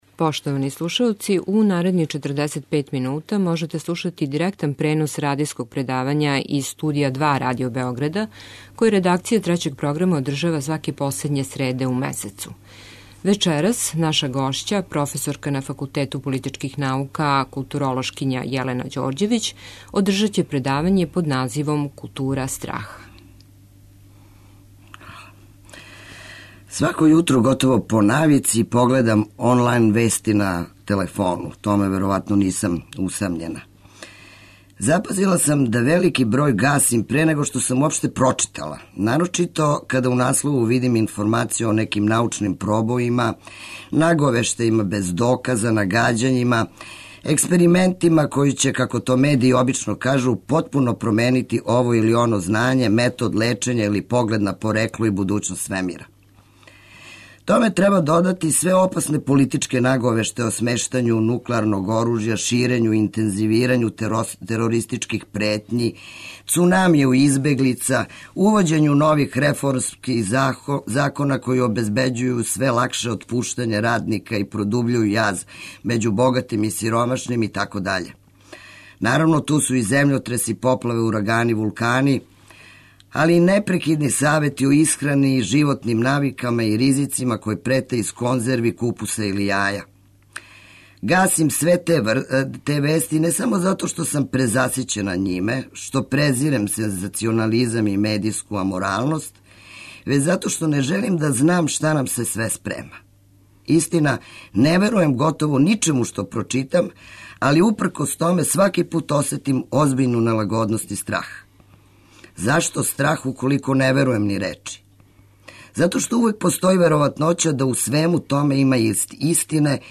Радијско предавање